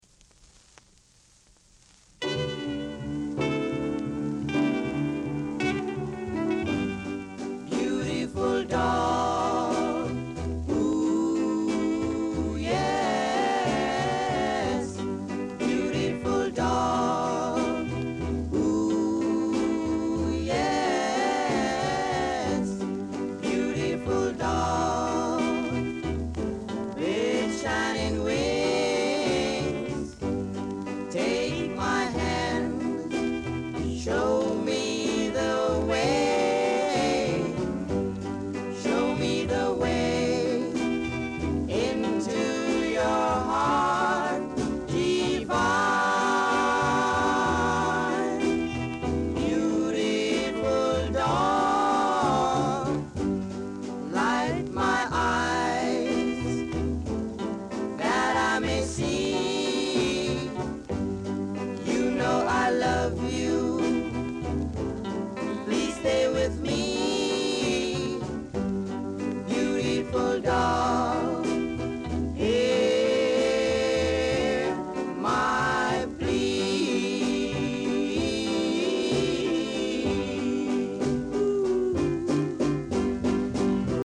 Doo Wop, R&B, Ska Male Vocal
Rare! nice Ja doo-wop ska vocal!